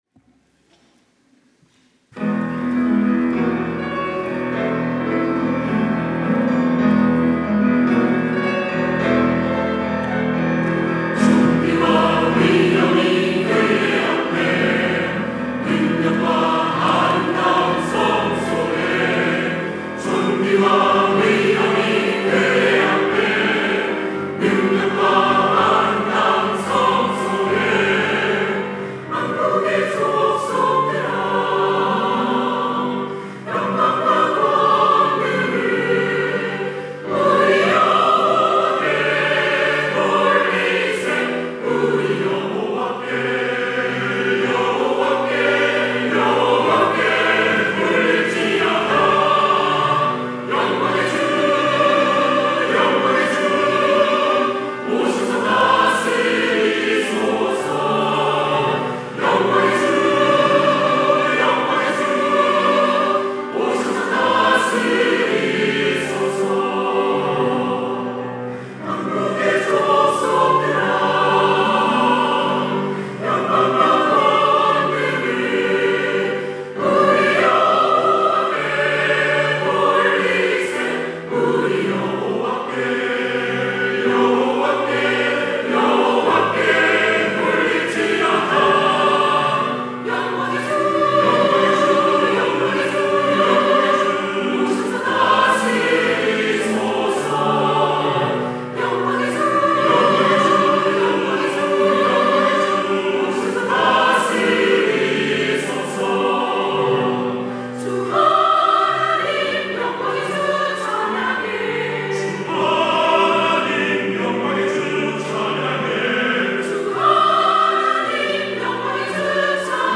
할렐루야(주일2부) - 영광의 주
찬양대